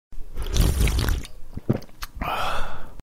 Звуки газировки